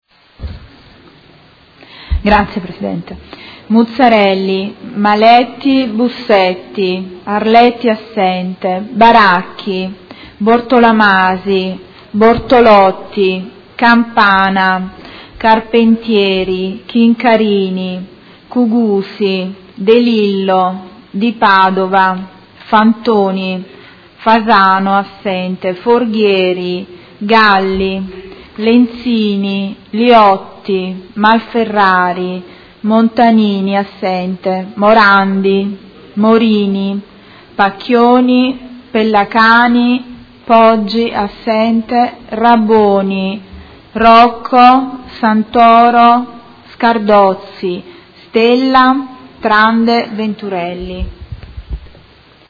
Segretario Generale — Sito Audio Consiglio Comunale
Seduta del 30/03/2017. Appello